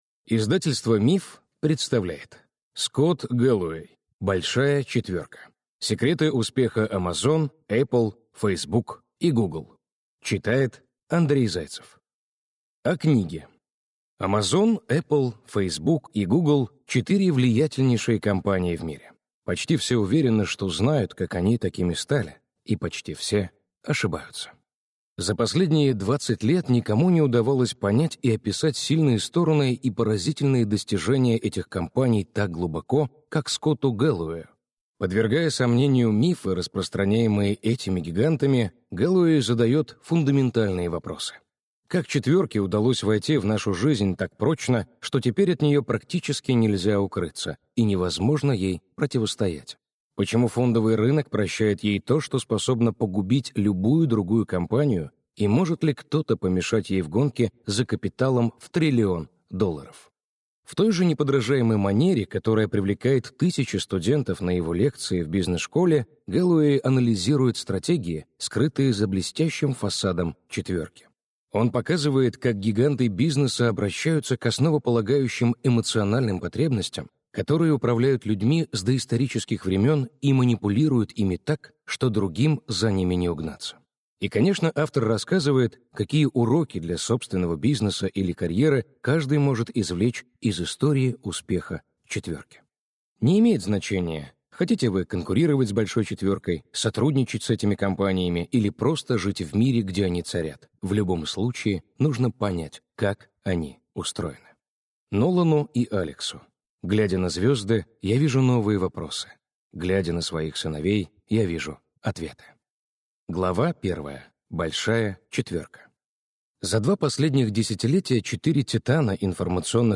Аудиокнига «Большая четверка» | Библиотека аудиокниг
Прослушать и бесплатно скачать фрагмент аудиокниги